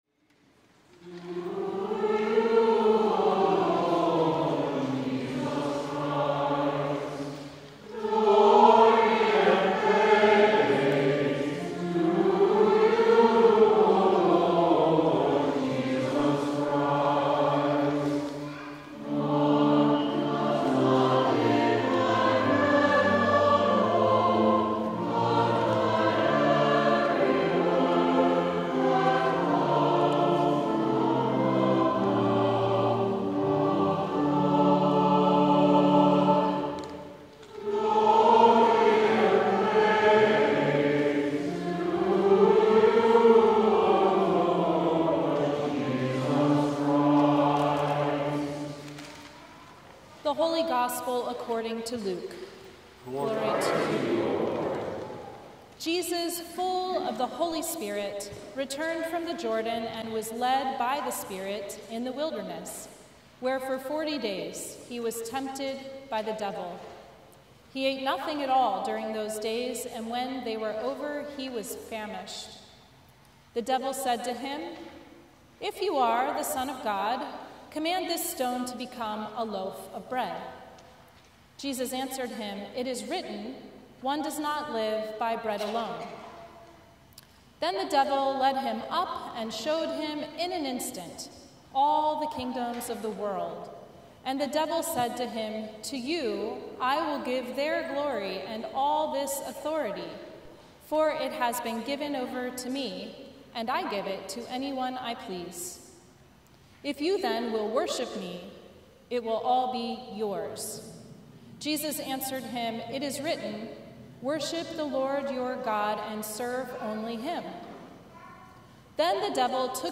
Sermon from the First Sunday in Lent 2025
CTK Livestream